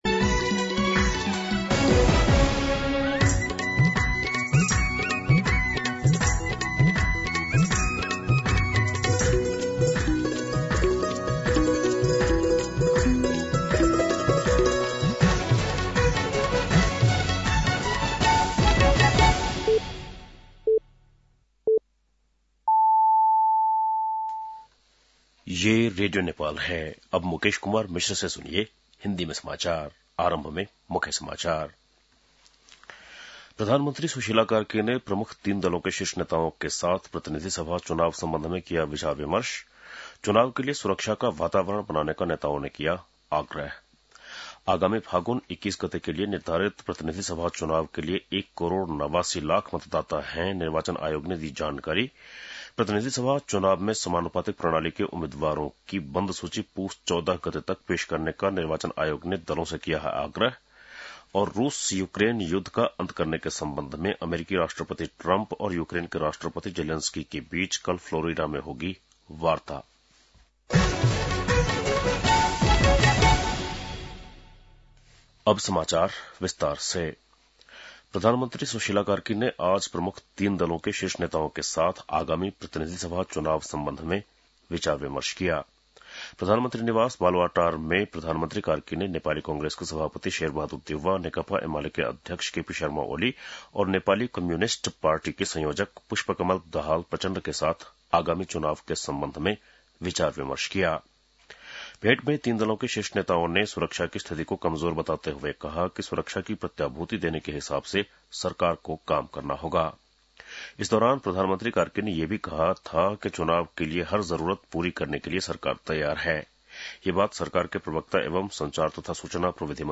बेलुकी १० बजेको हिन्दी समाचार : १२ पुष , २०८२